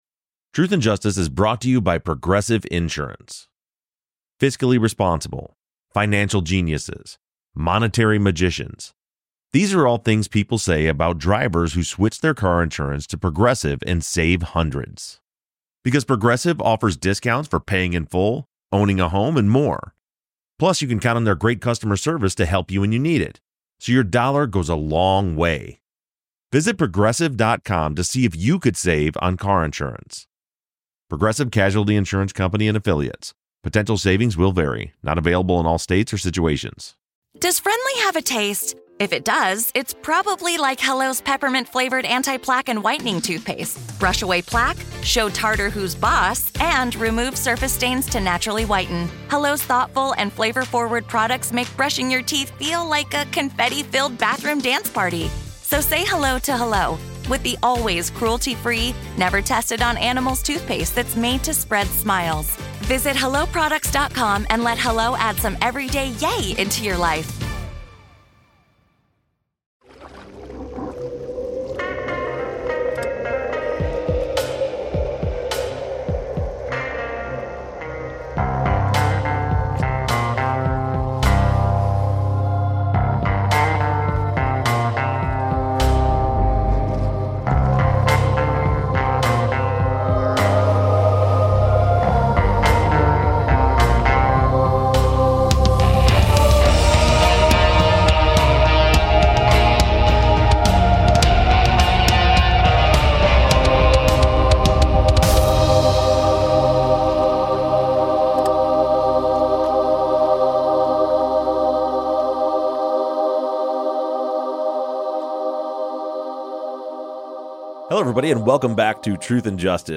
This week the guys record a Zoom call remotely to answer listener questions over the Forgotten West Memphis Three case.